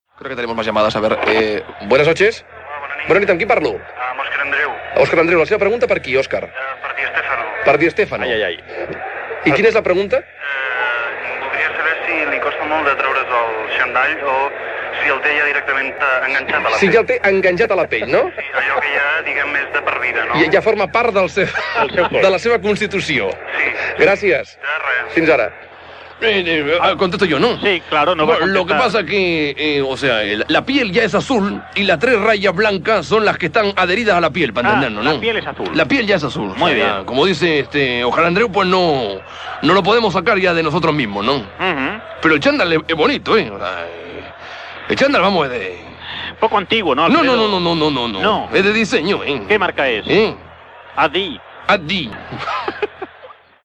Trucada telefònica d'Òscar Andreu i resposta d'Alfreo di Stéfano (imitació)
Entreteniment